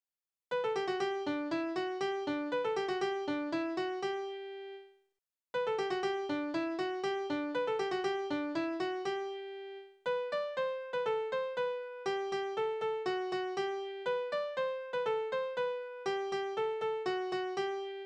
Tanzverse: Katze und Maus
Tonart: G-Dur
Taktart: 2/4
Tonumfang: Oktave
Besetzung: vokal